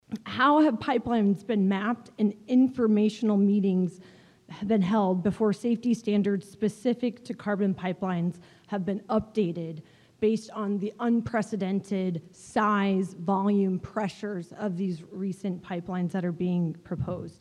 Landowners testify at pipeline safety hearing